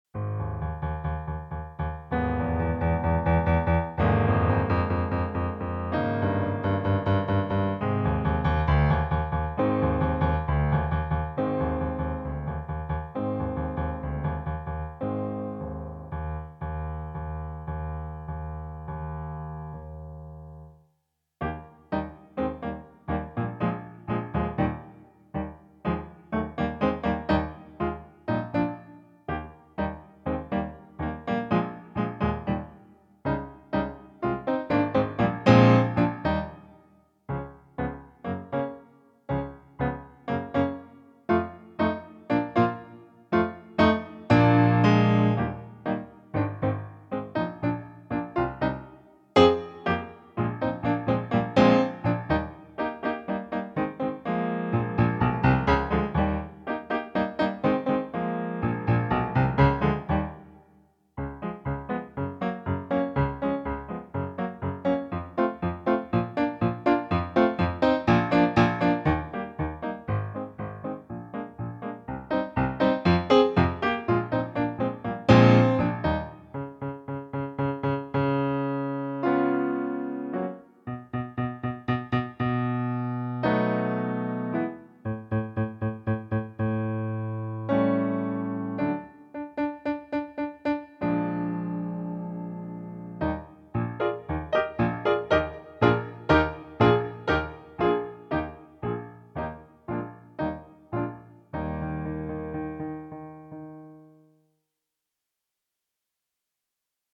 Stille, stille wir schreiten zur Rache (Instrumental)
06_stille_stille_wir_schreiten_zur_rache_instrumental.mp3